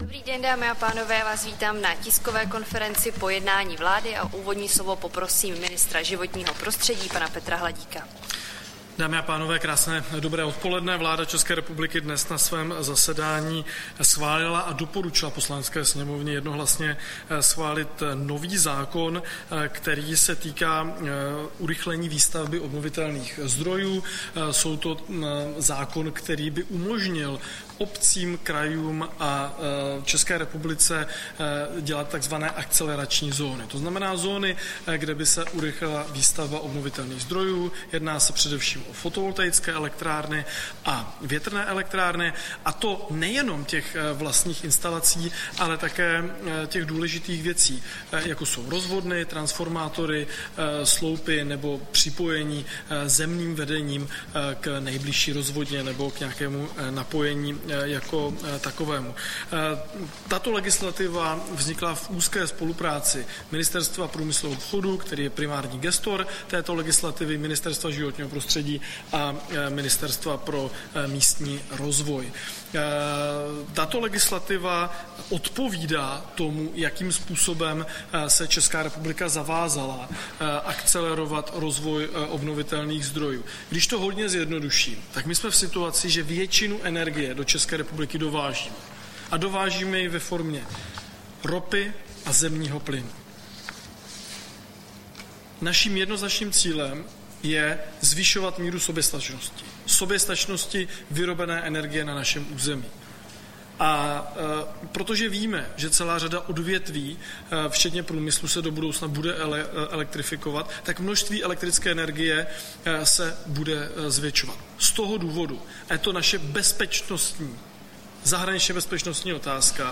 Tisková konference po jednání vlády, 12. března 2025